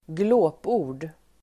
Uttal: [²gl'å:po:r_d]